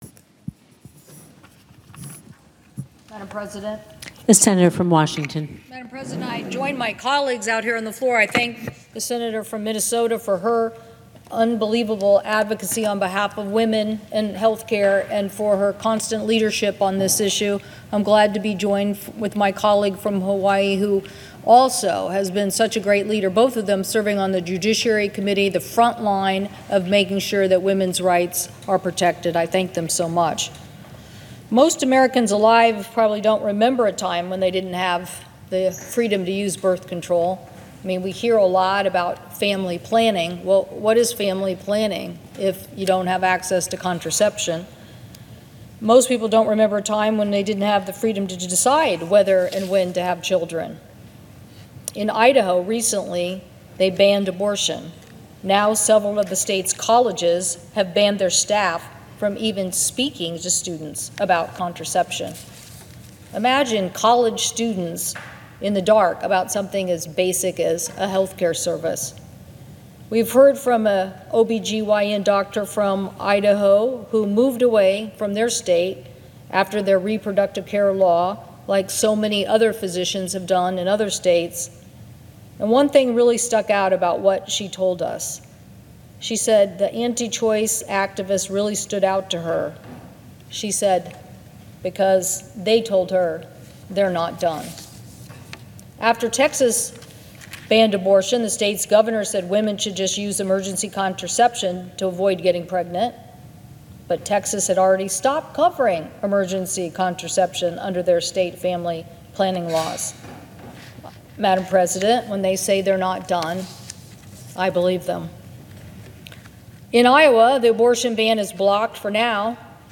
6524-right-to-contraception-floor-speech-audio&download=1